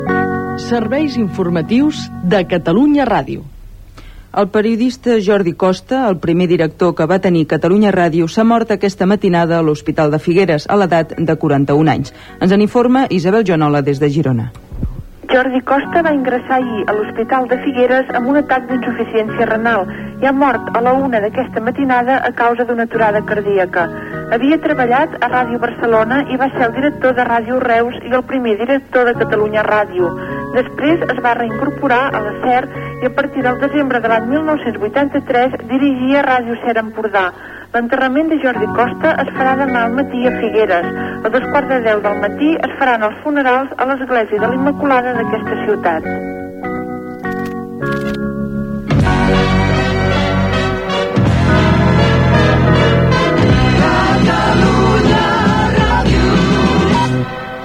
Indicatiu del programa, informació de la mort del periodista Jordi Costa, primer director de Catalunya Ràdio, a Figueres, Indicatiu de l'emissora
Informatiu